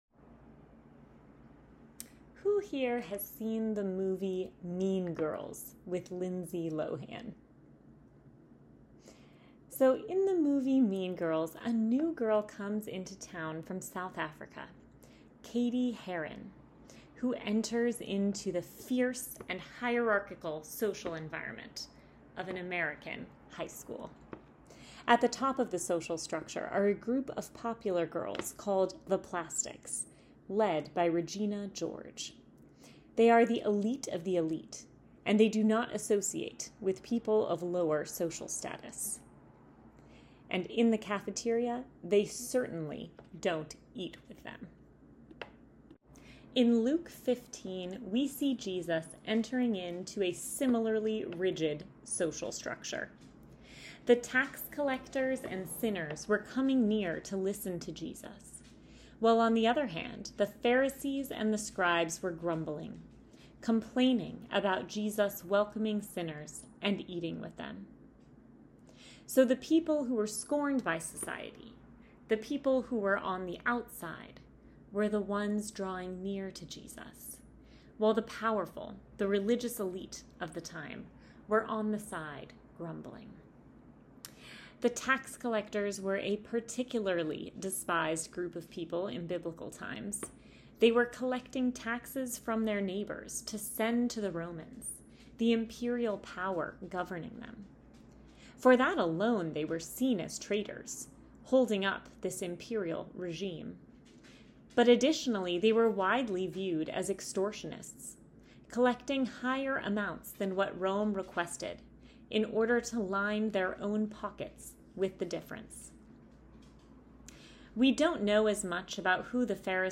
Sermon Podcast | Church of St. James the Less